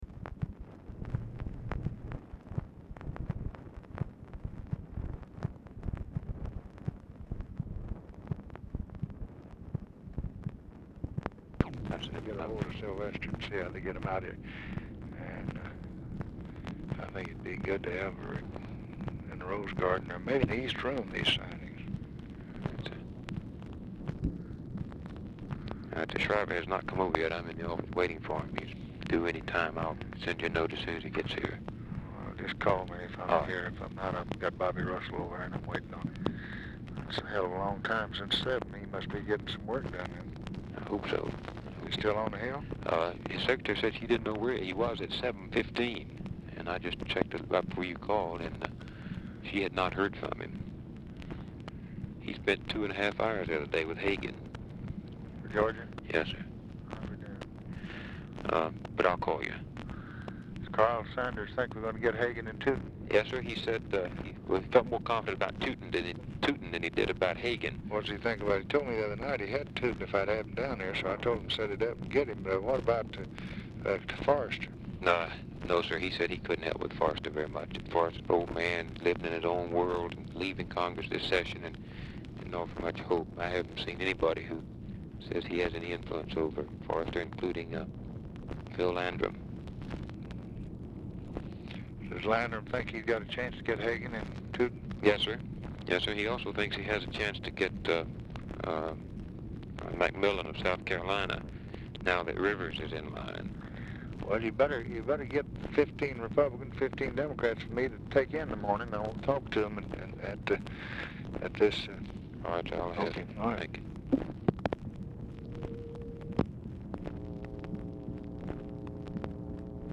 Telephone conversation # 4653, sound recording, LBJ and BILL MOYERS, 8/3/1964, 8:22PM
Format Dictation belt
Location Of Speaker 1 Oval Office or unknown location